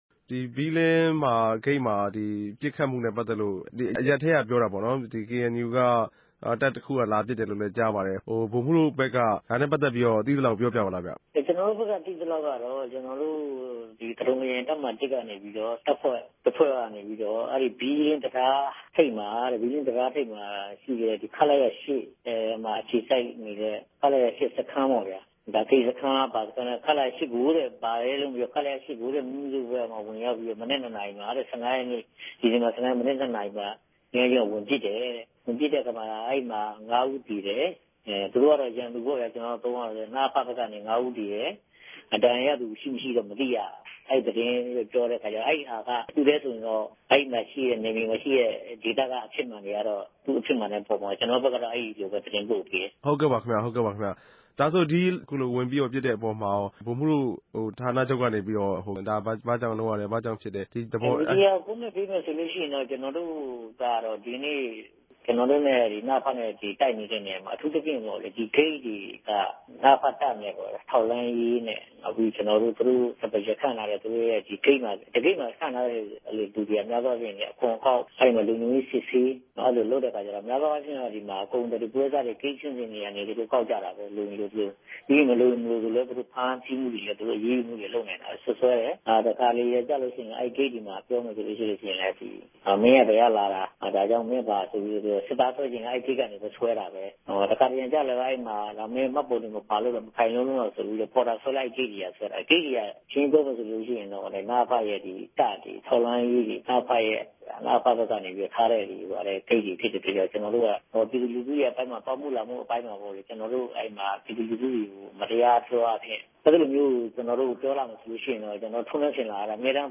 ဒီပစ်ခတ်မနြဲႛ ပတ်သက်္ဘပီး ဘီလင်း္ဘမိြႚခံတဦးက အခုလို ေူပာူပပၝတယ်။